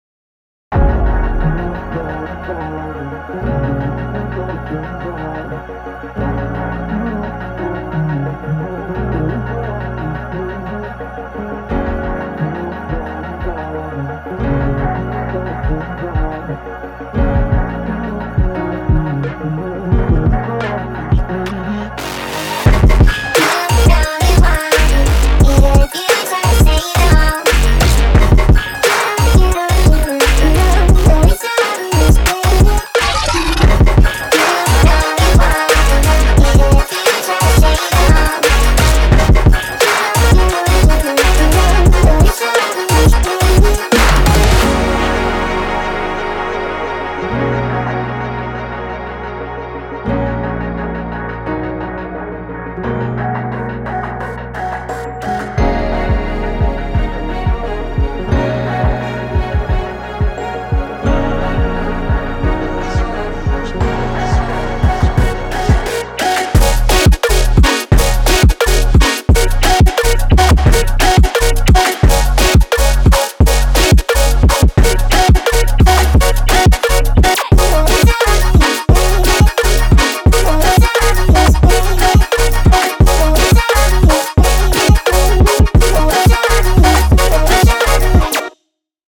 hyperpop colorbass trap pop music electronic idm